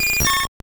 Cri de Papilusion dans Pokémon Or et Argent.